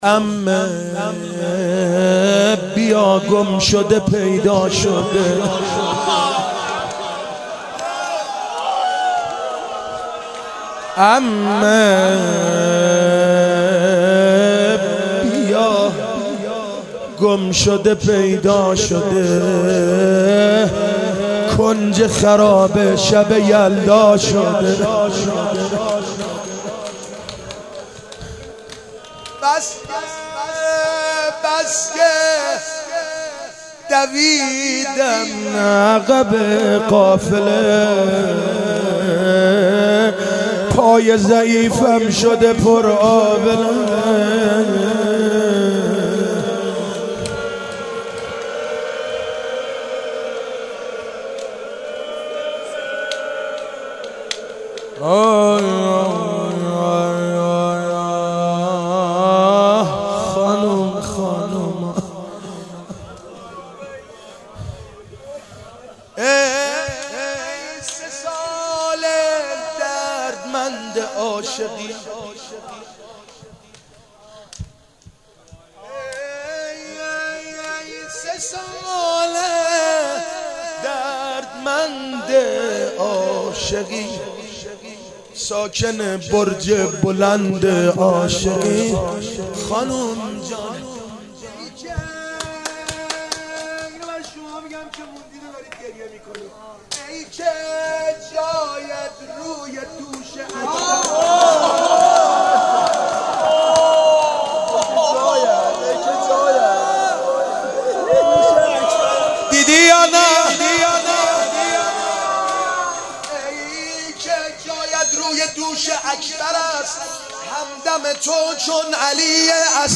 مناسبت : شب سوم محرم
قالب : روضه